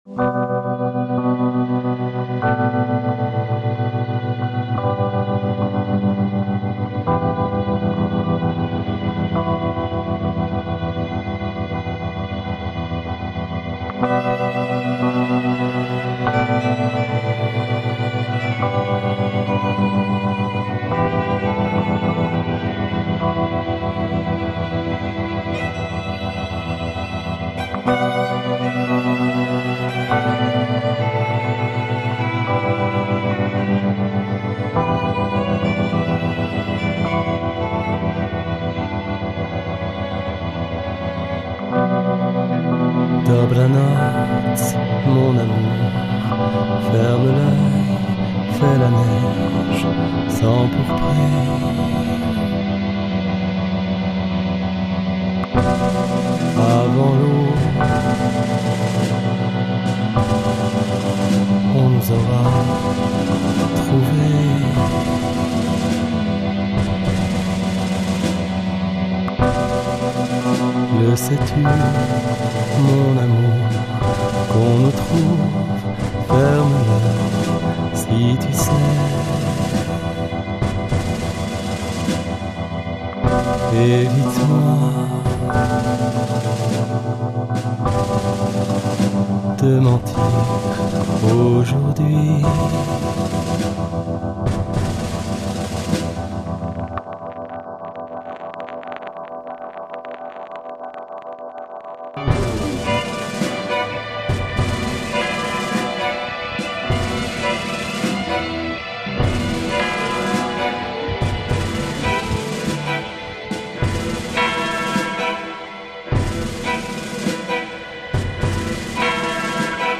remixé
A partir de la piste chant du morceau